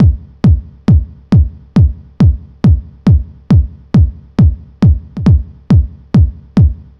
Kick 137-BPM 1.wav